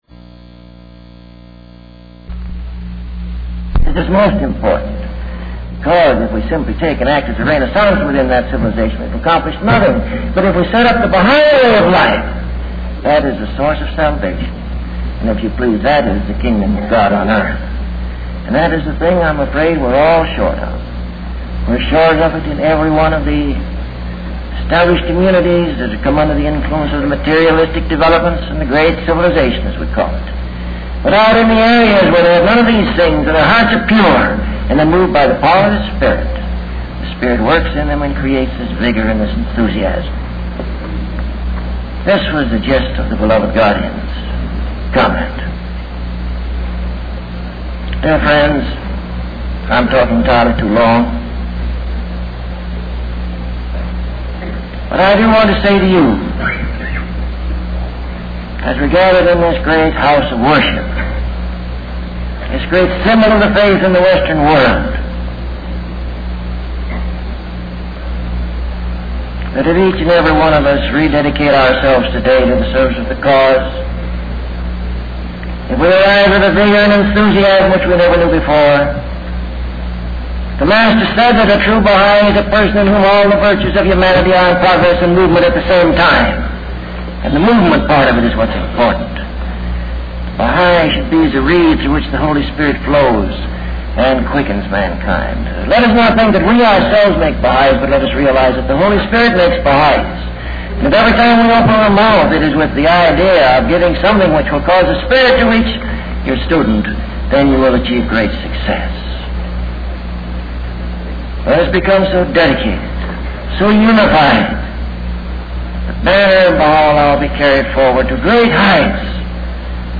Excerpt from a longer speech.